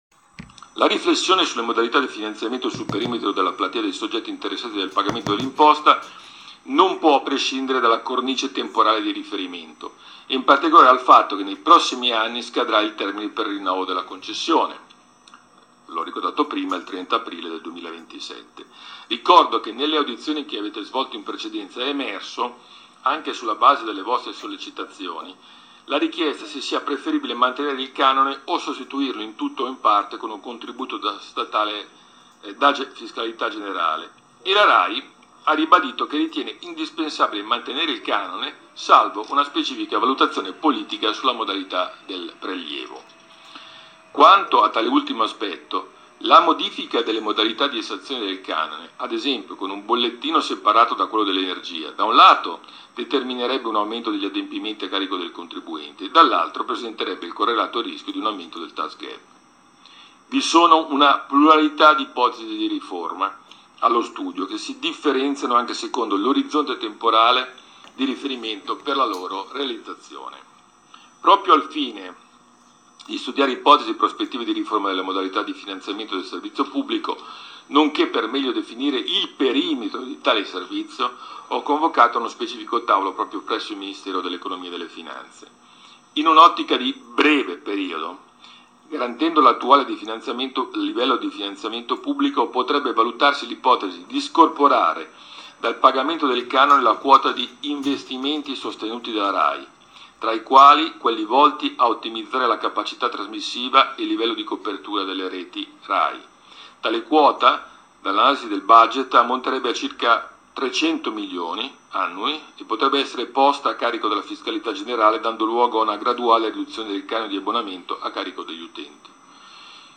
Giancarlo Giorgetti, Ministro dell’Economia e della Finanze, nella giornata del 27 luglio 2023 alle 08:30, è intervenuto in un’audizione per la commissione parlamentare per l’indirizzo generale e la vigilanza dei servizi radiotelevisivi, parlando del canone RAI che attualmente si paga nelle bollette di energia elettrica.
ministro-giorgetti-audizione-canone-rai-probabile-linee-telefoniche.mp3